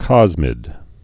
(kŏzmĭd)